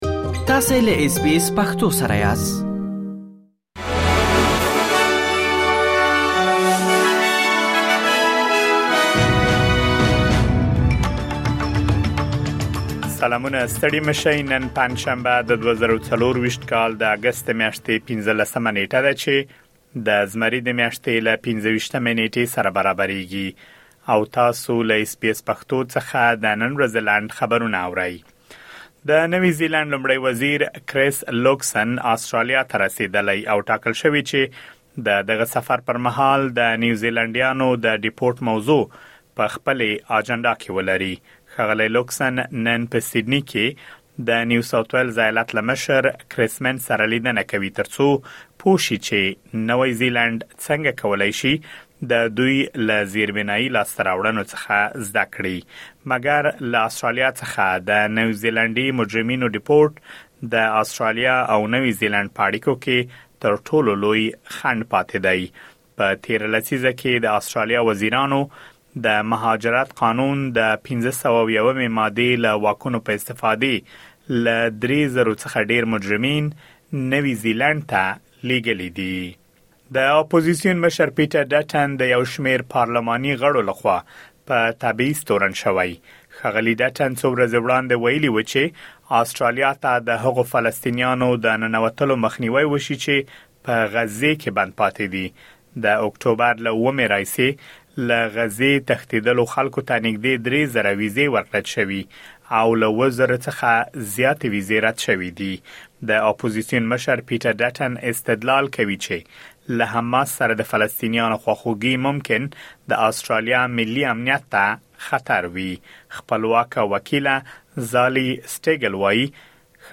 د اس بي اس پښتو د نن ورځې لنډ خبرونه|۱۵ اګسټ ۲۰۲۴
د اس بي اس پښتو د نن ورځې لنډ خبرونه دلته واورئ.